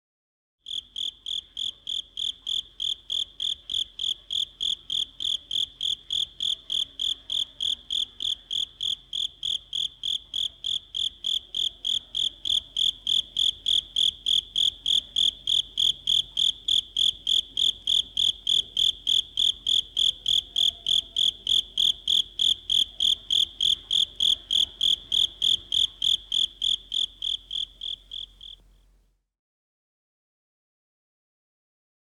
80-cricket.mp3